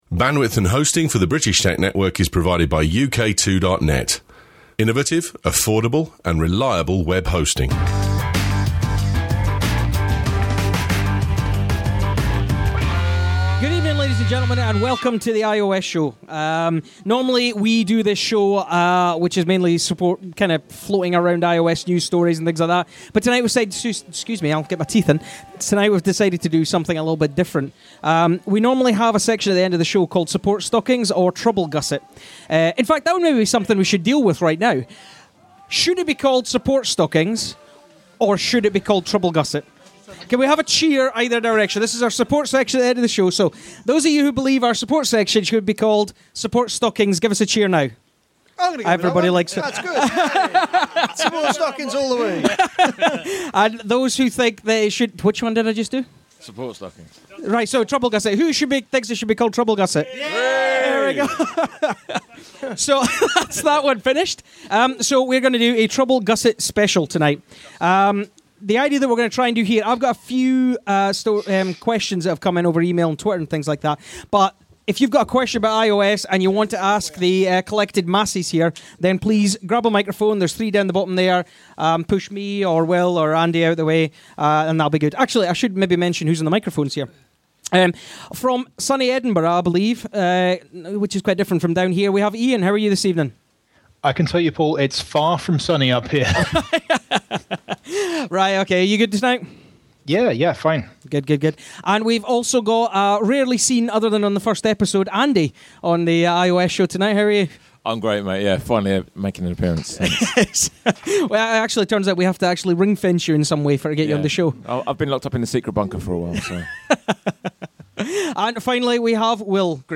Along with some heckling from the crowd the panel cover note taking apps, getting the most out of your iPhones battery and streaming to TVs.